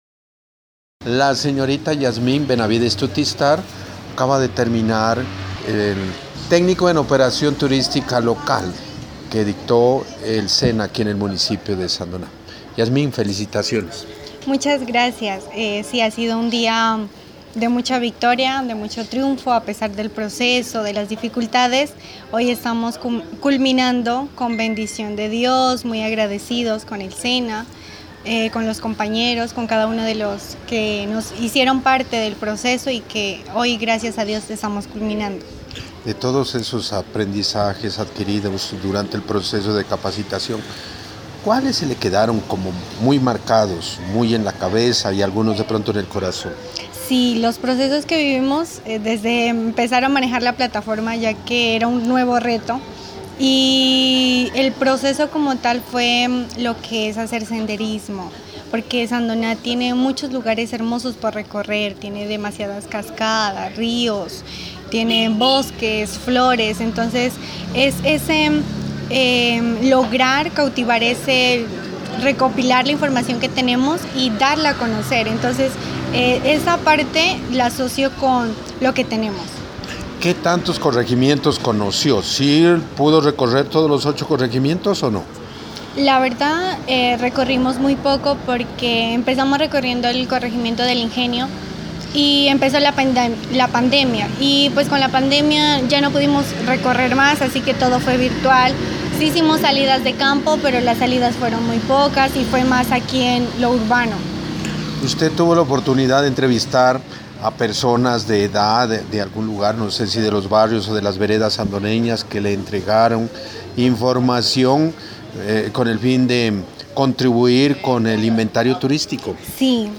En el salón de eventos del barrio Campo Alegre al sur de Sandoná se desarrolló la ceremonia de graduación de 19 técnicos en operación turística local del Servicio Nacional de Aprendizaje – Sena.